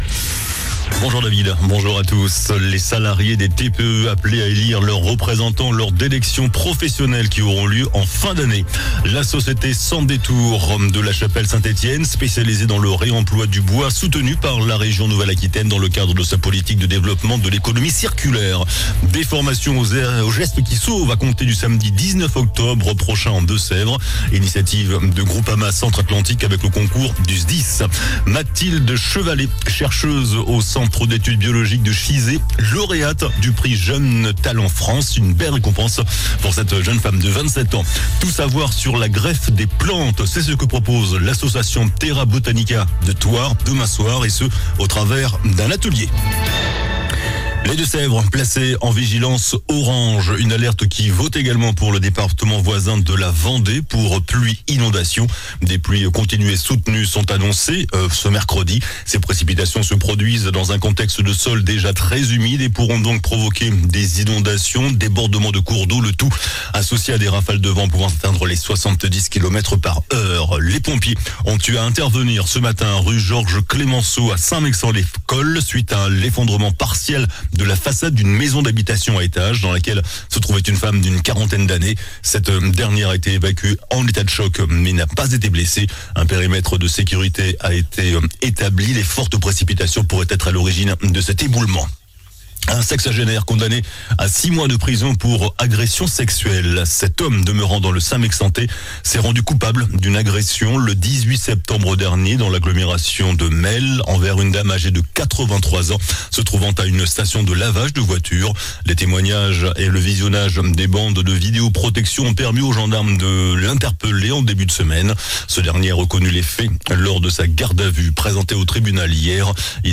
JOURNAL DU MERCREDI 09 OCTOBRE ( MIDI )